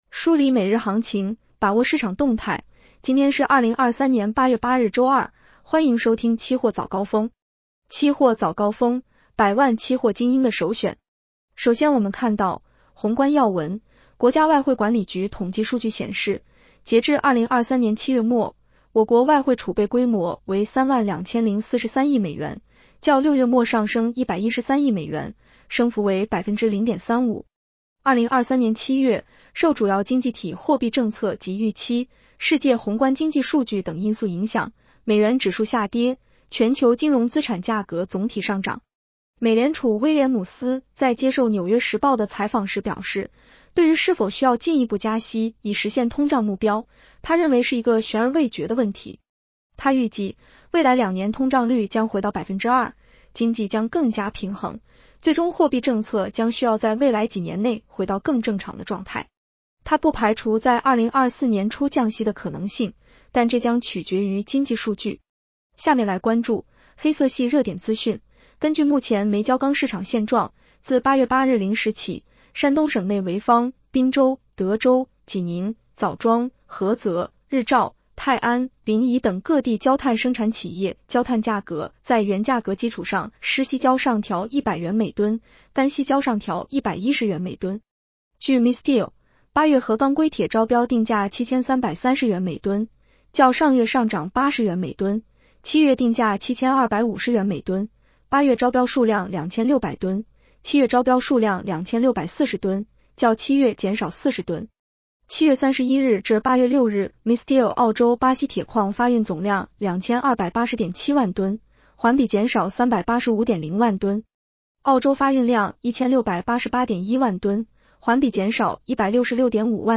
【期货早高峰-音频版】 女声普通话版 下载mp3 宏观要闻 1.